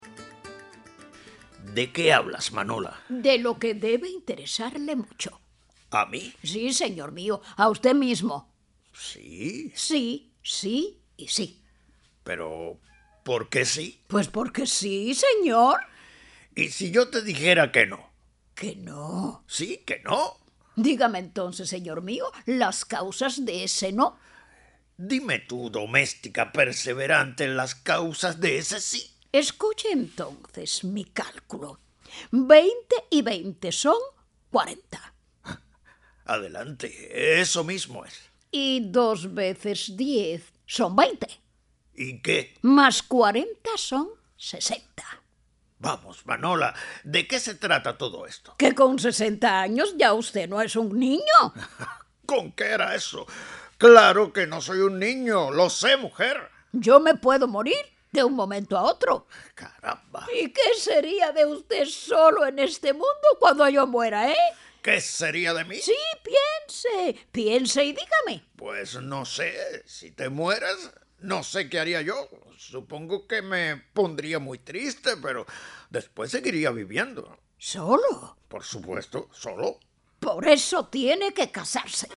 NOVELA.mp3